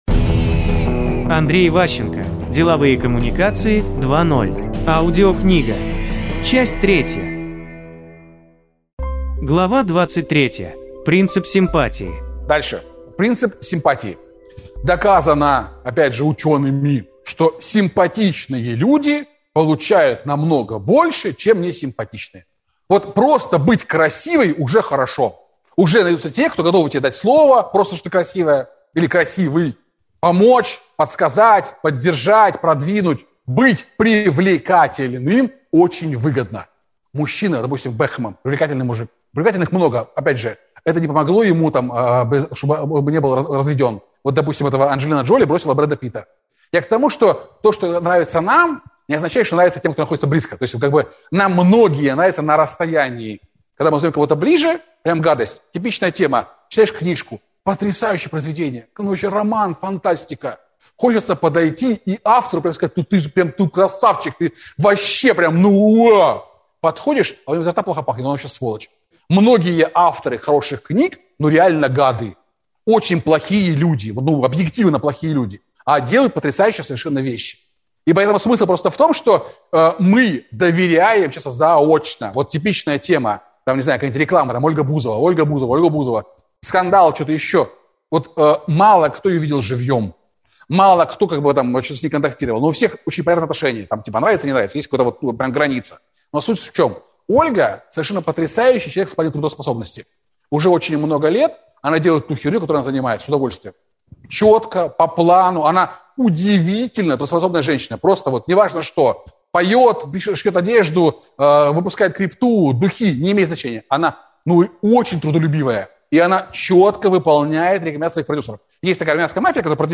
Аудиокнига Деловые коммуникации 2.0. Часть 3 | Библиотека аудиокниг